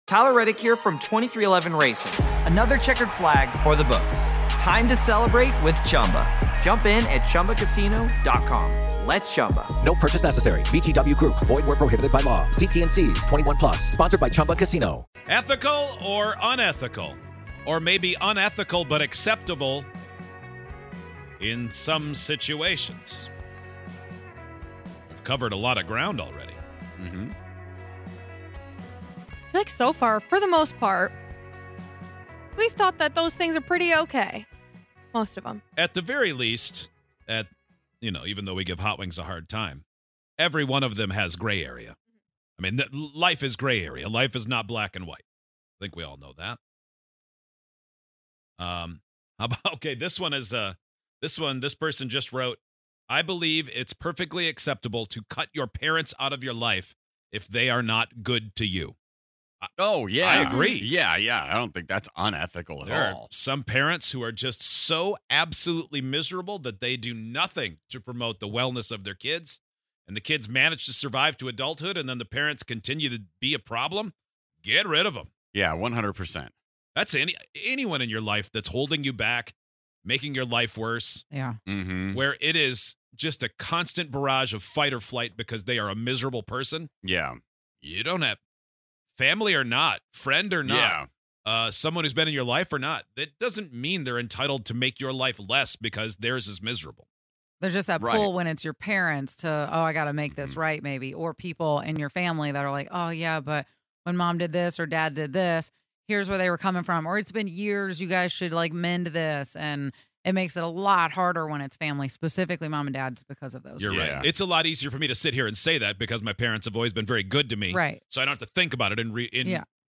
While we certainly should not be anyone's moral compass, we have five idiots' opinions on what is ethical or not. Or, at least four idiots and one guy who loves to say "it depends..."